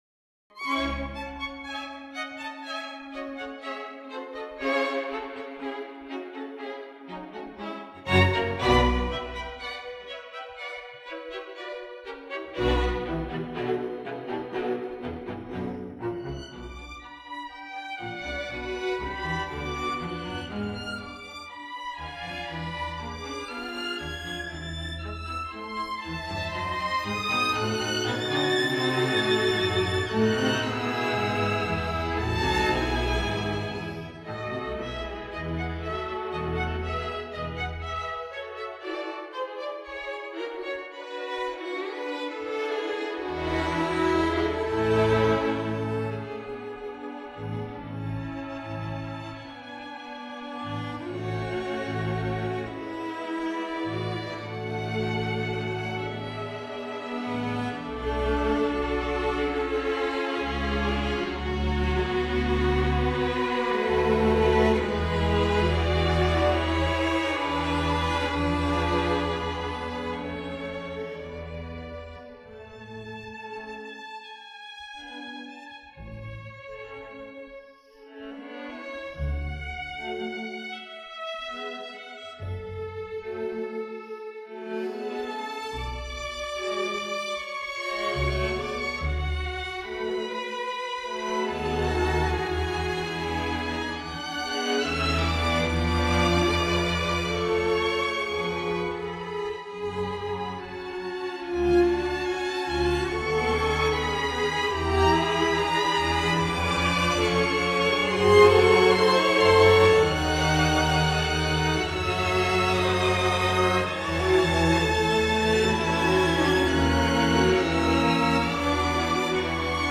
Audio Track: Music